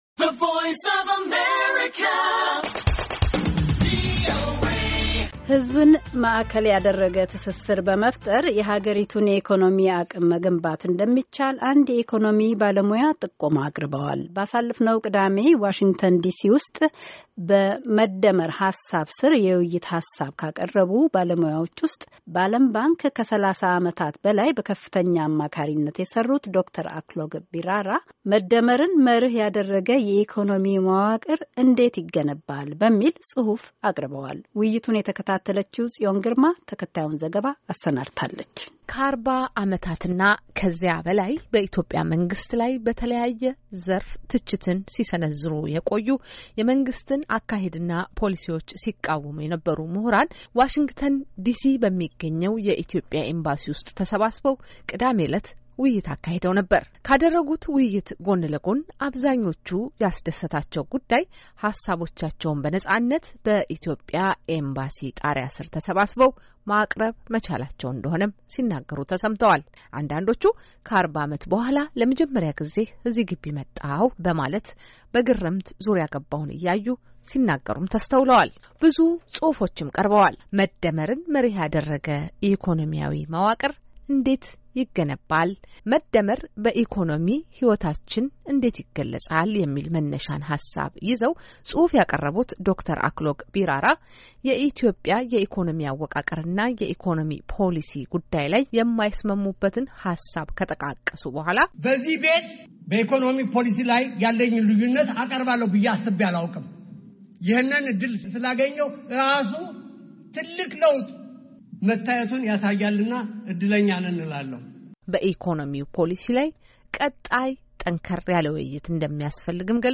ዋሽንግተን ዲሲ በሚገኘው የኢትዮጵያ ኤምባሲ ውስጥ ባለፈው ቅዳሜ በተለያዩ ጉዳዮች ላይ ውይይት ተካሂዶ ነበር።